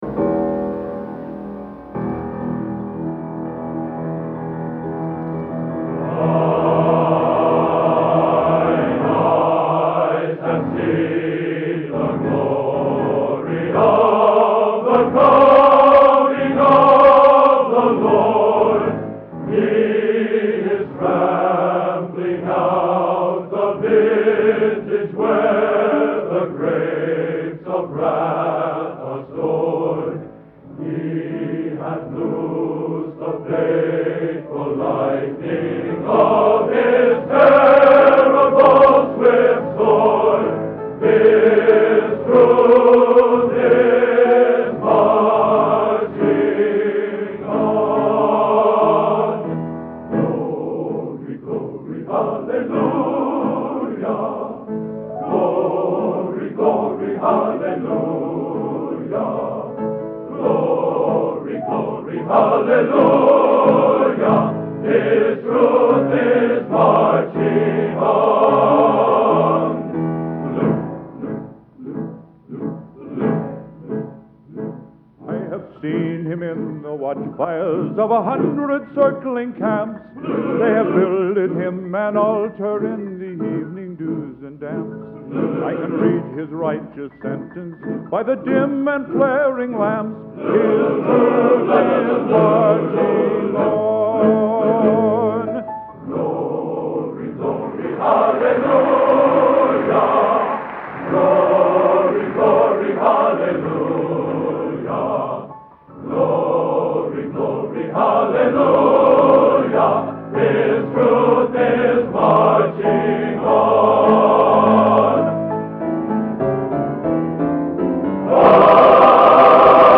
Location: West Lafayette, Indiana
Genre: | Type: End of Season